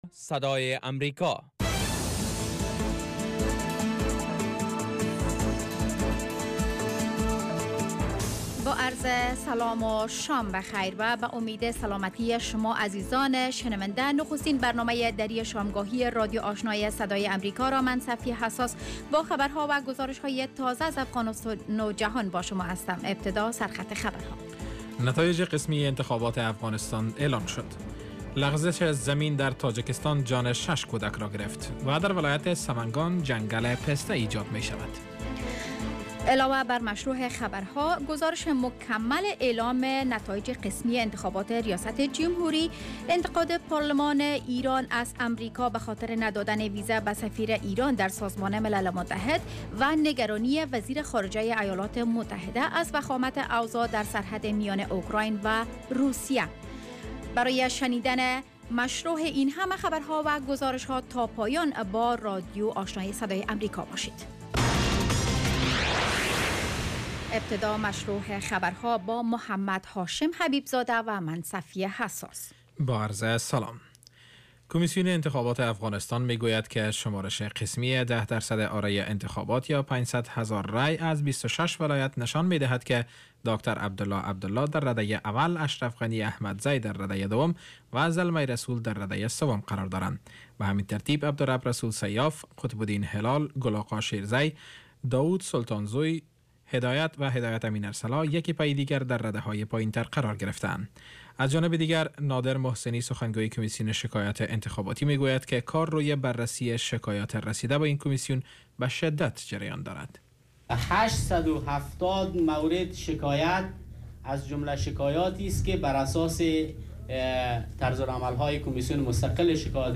Radio evening first news half-hour show